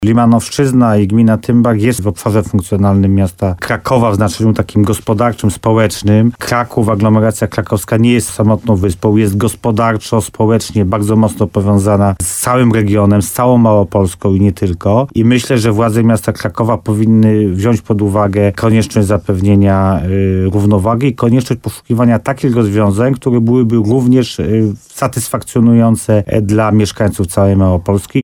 Jak mówił w programie Słowo za Słowo w RDN Nowy Sącz wójt gminy Tymbark Paweł Ptaszek, rezolucja nie ma możliwości sprawczych, ale jej sensem jest ukazanie bardzo ważnego problemu, z którym borykają się mieszkańcy.